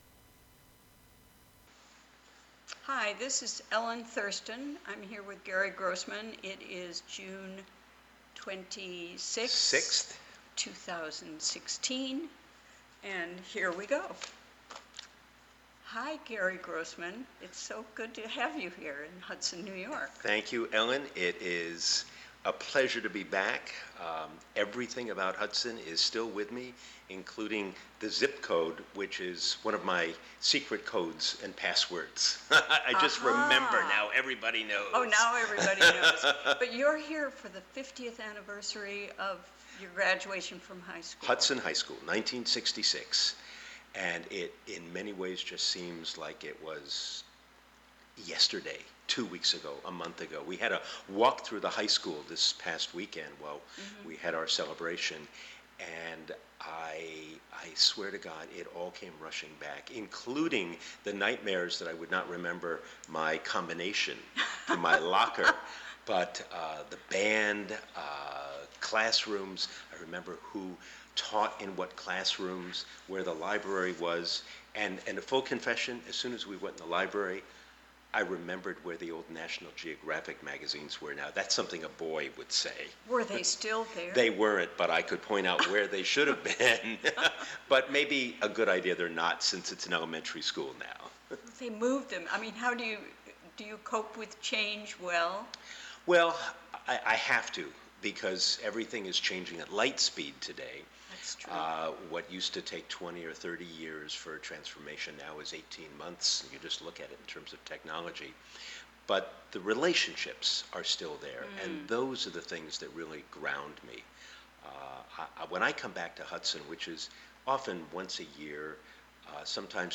6pm The show features local news, interviews with comm...